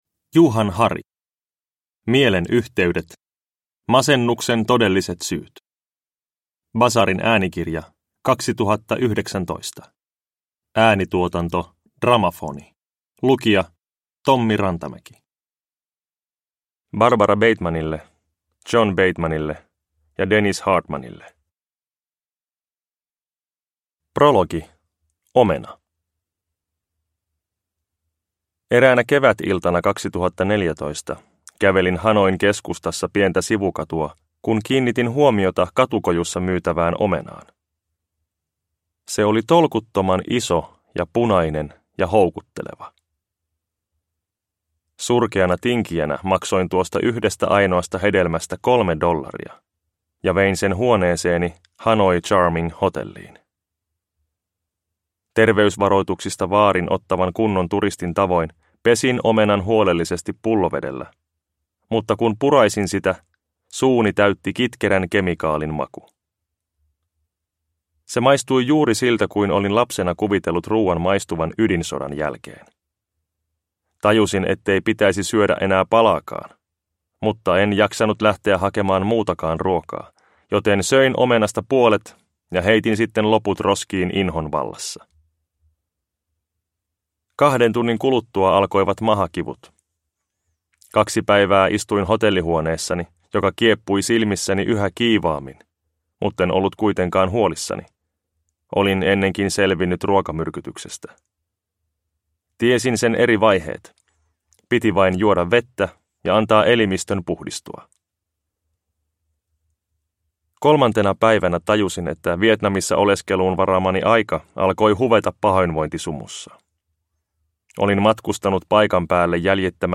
Mielen yhteydet – Ljudbok – Laddas ner